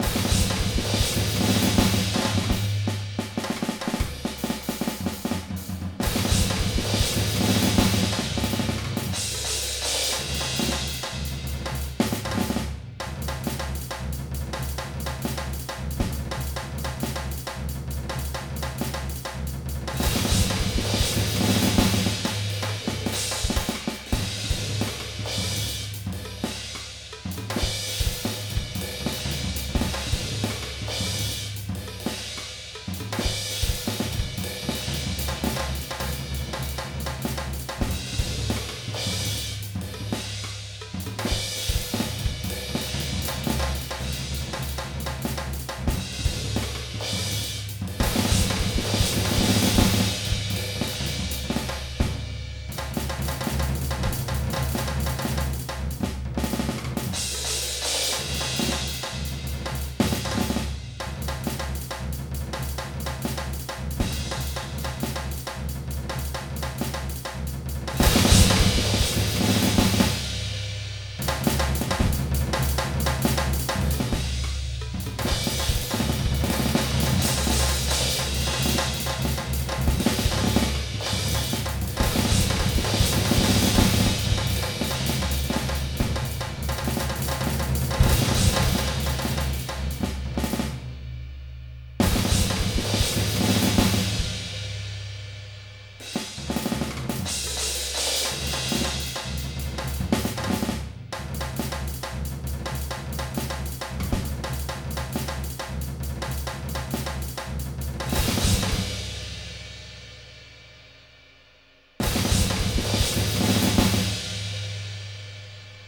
drumsofdeerhead.ogg